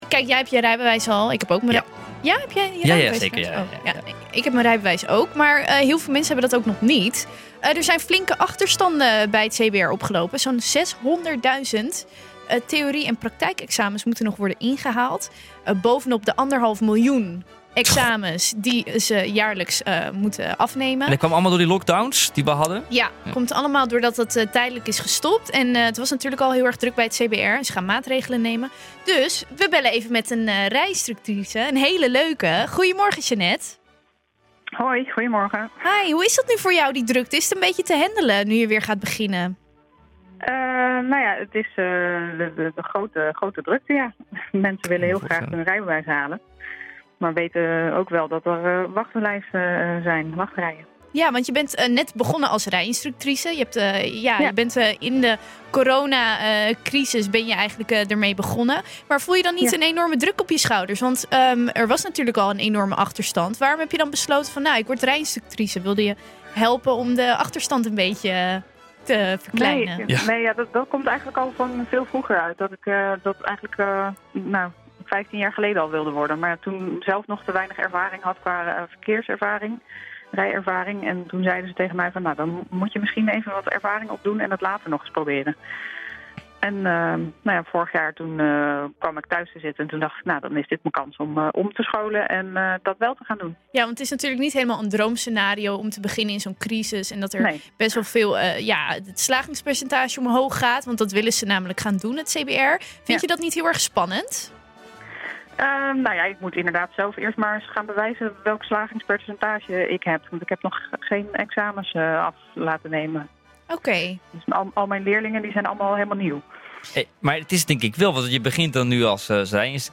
Het CBR gaat maatregelen nemen. Dus bellen we even met een rij-instructrice!